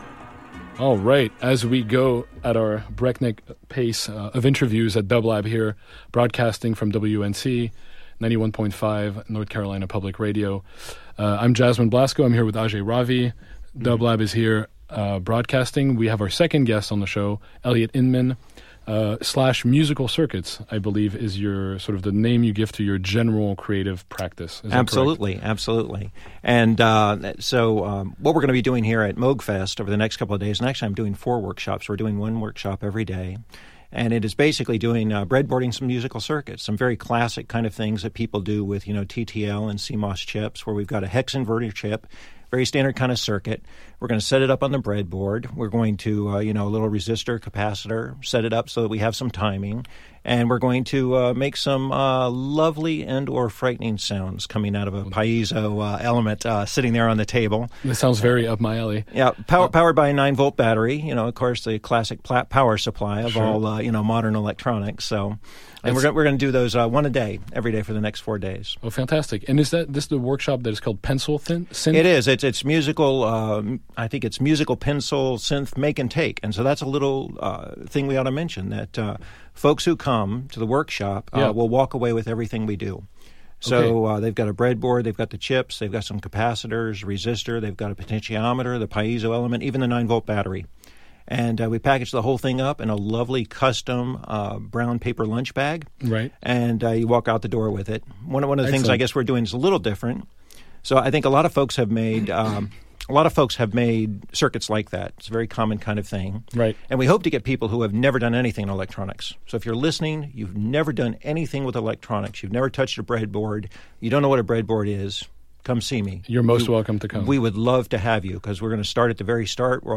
Musical Circuits Goes to Moogfest 2017
Interview